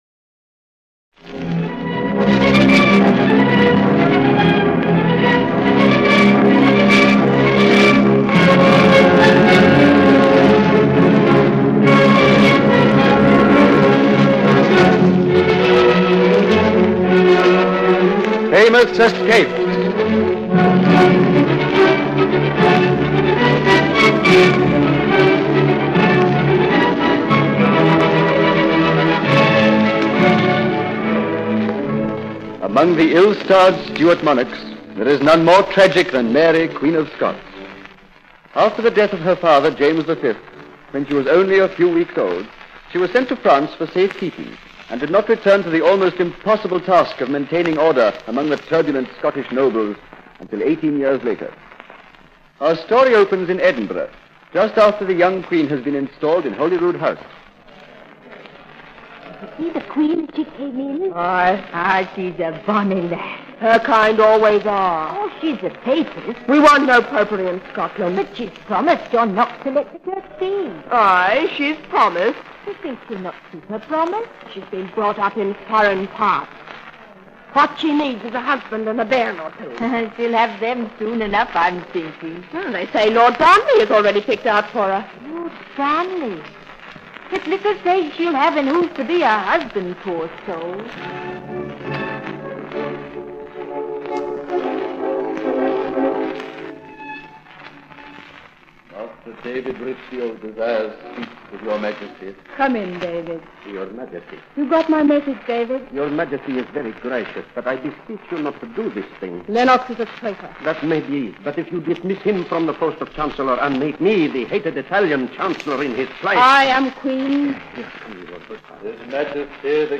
Famous Escapes was a captivating radio series produced in Australia around 1945. The show delved into some of the most daring escapes in history, featuring remarkable characters who managed to break free from seemingly impossible situations.